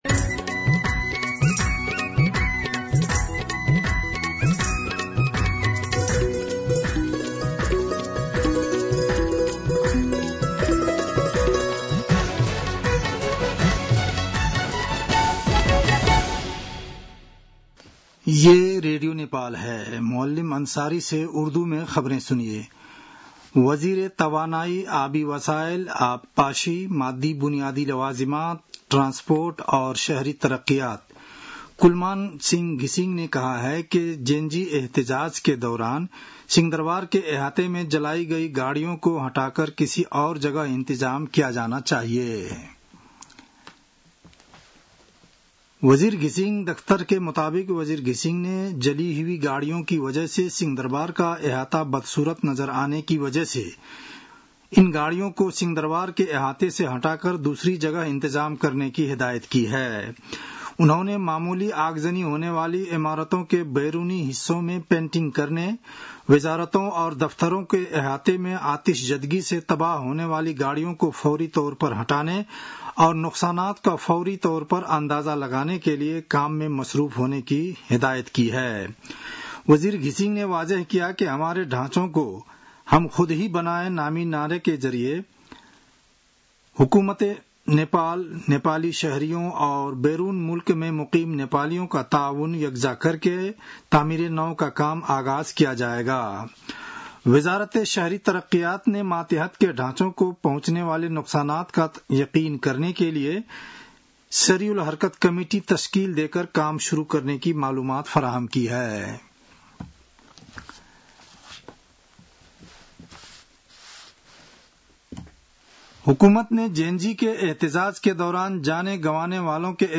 An online outlet of Nepal's national radio broadcaster
उर्दु भाषामा समाचार : १ असोज , २०८२